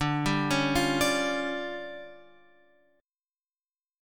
D Minor Major 7th Double Flat 5th